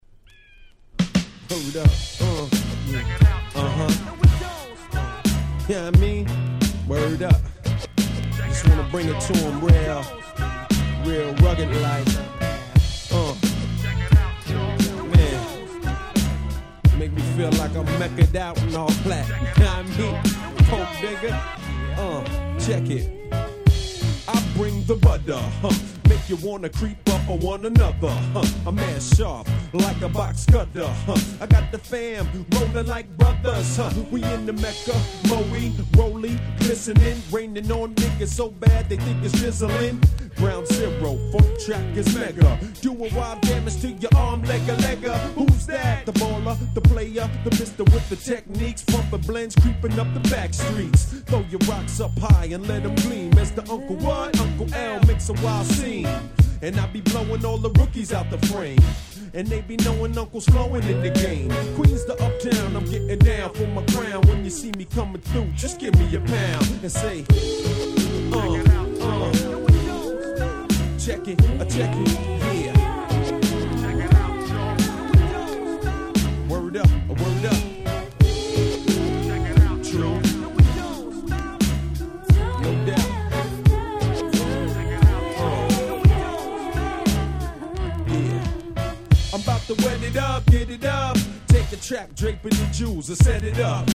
95' Super Hit Hip Hop LP !!